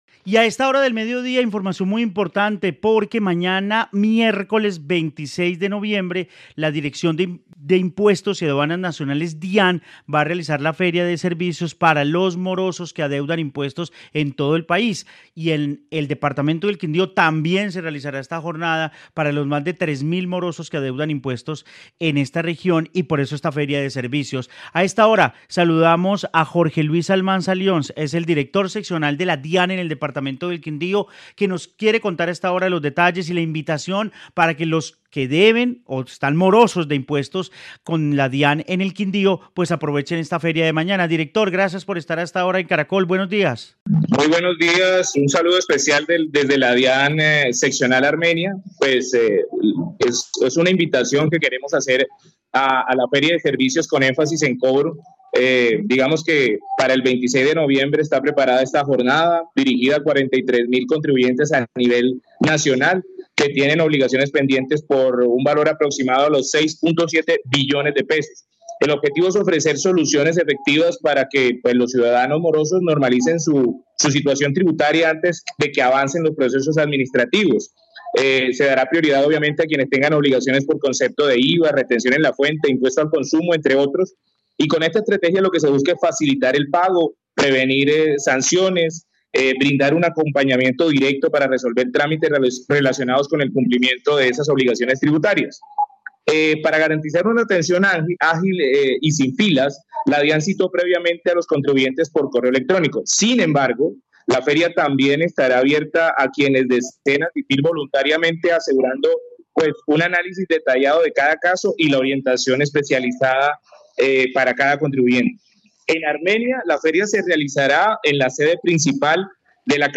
Jorge Luis Almanza, director regional de la DIAN
En el noticiero del mediodía de Caracol Radio Armenia hablamos con director seccional de la DIAN Armenia, Jorge Luis Almanza Lyons que entregó detalles de la jornada para 3000 morosos que hay en el departamento “es una invitación que queremos hacer a la feria de servicios con énfasis en cobro el miércoles 26 de noviembre dirigida 43000 contribuyentes a nivel nacional que tienen obligaciones pendientes por un valor aproximado a los 6.7 billones de pesos.